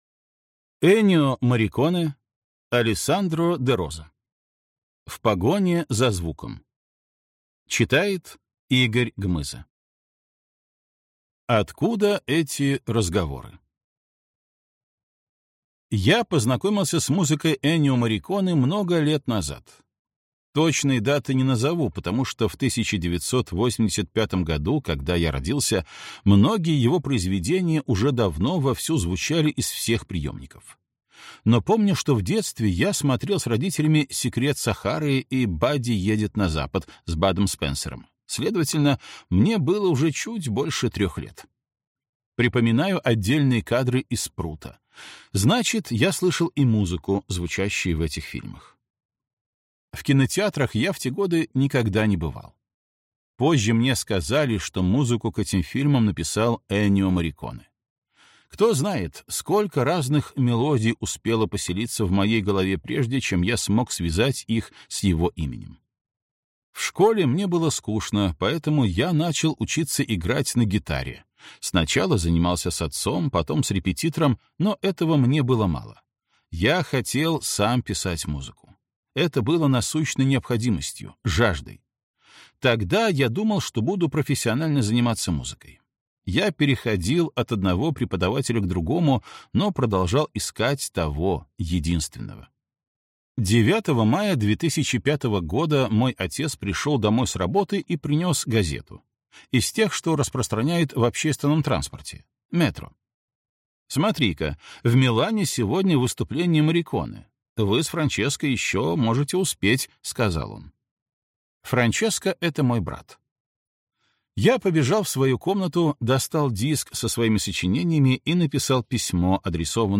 Аудиокнига В погоне за звуком | Библиотека аудиокниг